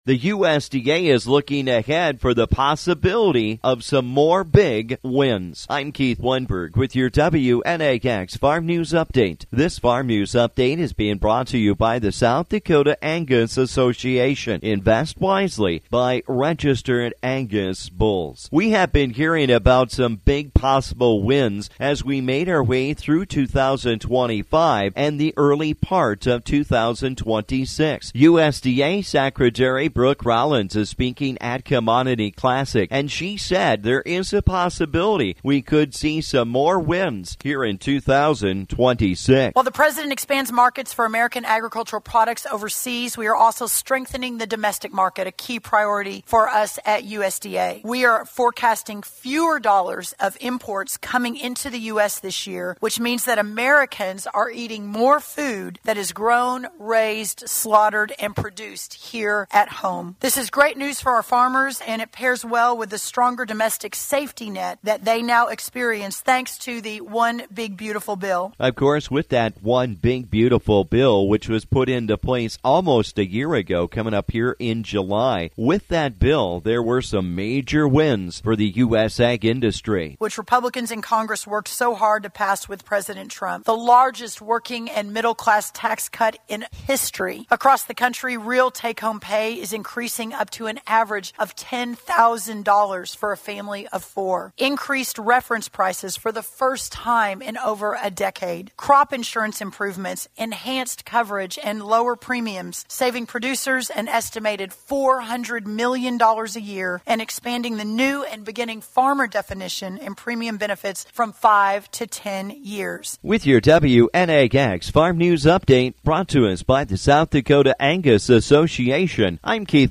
USDA Secretary Brooke Rollins spoke yesterday at Commodity Classic, and she took some time to review some of the Big U.S. Ag Industry Wins.